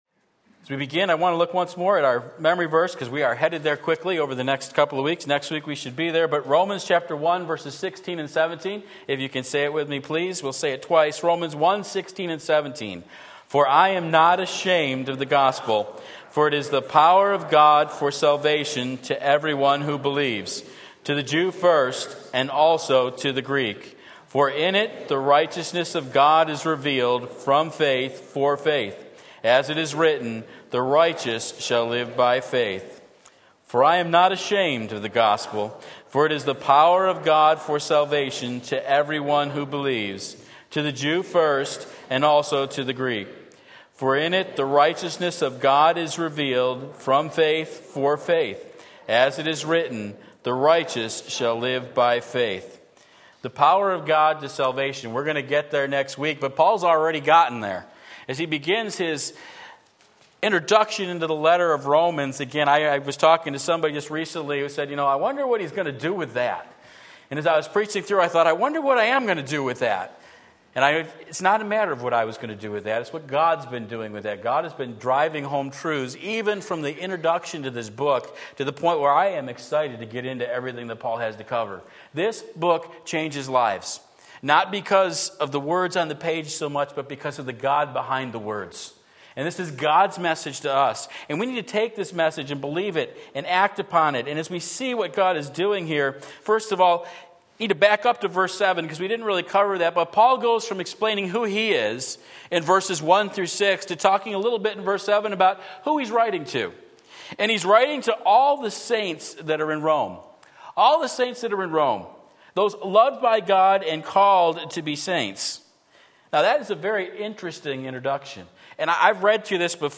Sermon Link
Paul's Relationship to the Romans Romans 1:7-13 Sunday Morning Service